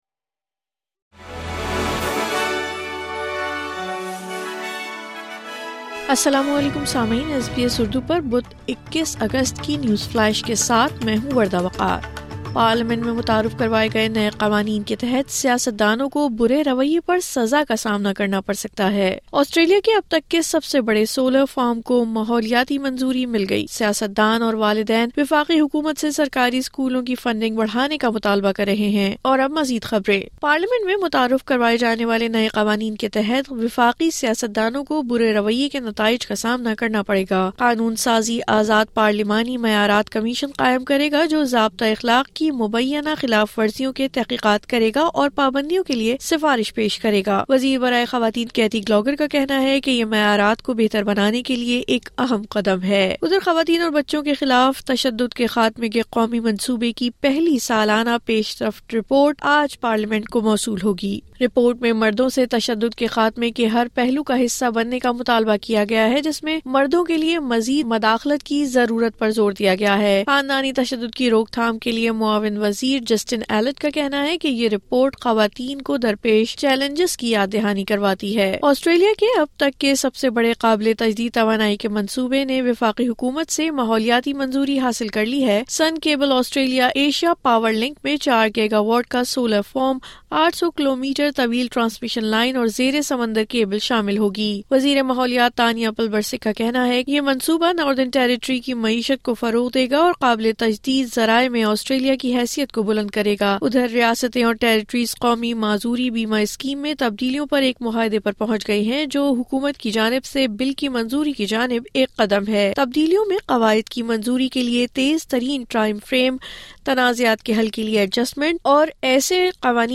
نیوز فلیش 21 اگست 2024: سیاست دانوں کو برے رویے پر سزا مل سکتی ہے، نیا قانون پارلیمان میں متعارف